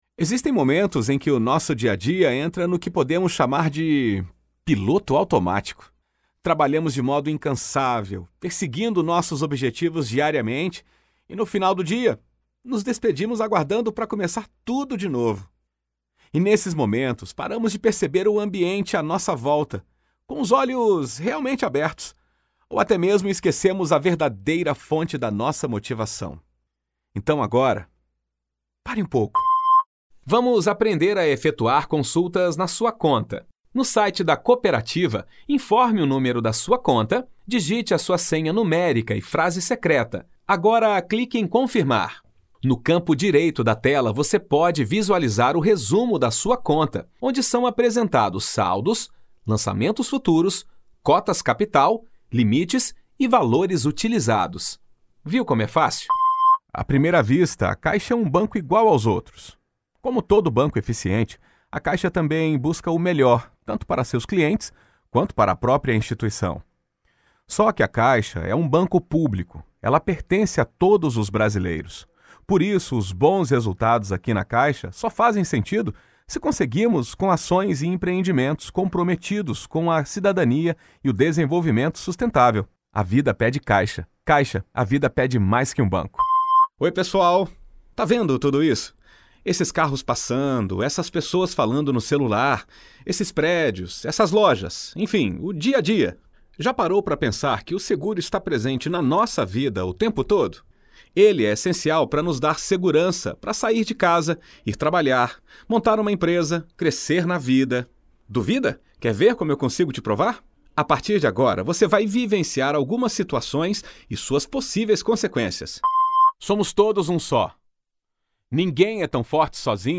A natural and versatile voice without vices or exaggerations, neutral accent to perform many different styles characters and ages, always giving the right approach to engage the audience.
Sprechprobe: Sonstiges (Muttersprache):